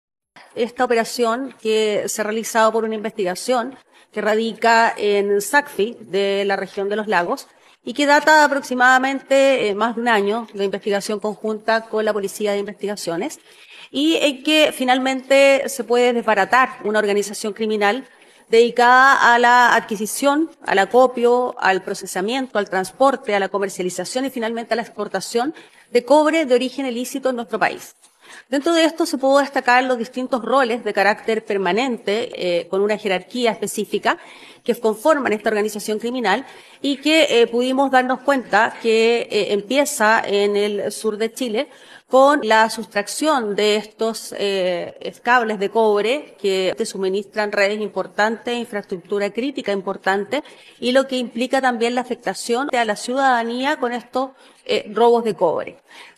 Según indicó la fiscal regional (S) María Angélica de Miguel, estas diligencias permitieron incautar 187 toneladas de cobre, el que era robado en varios puntos del sur de Chile.